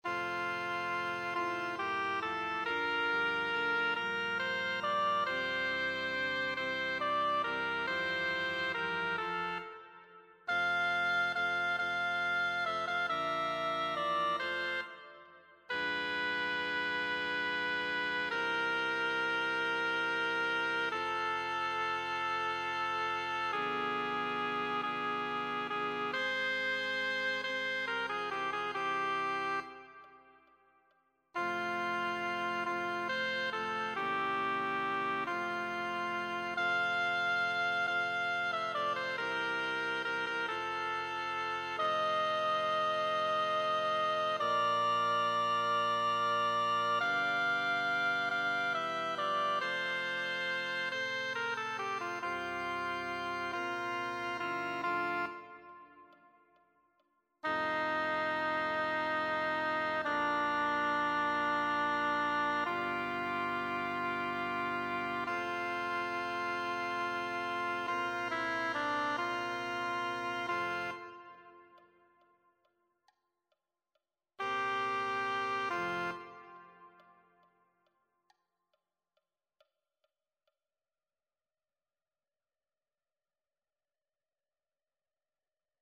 Singwoche pro Musica 2026 - Noten und Übungsdateien
Sopran 1